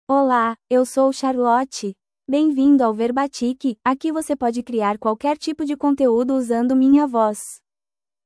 FemalePortuguese (Brazil)
CharlotteFemale Portuguese AI voice
Charlotte is a female AI voice for Portuguese (Brazil).
Voice sample
Charlotte delivers clear pronunciation with authentic Brazil Portuguese intonation, making your content sound professionally produced.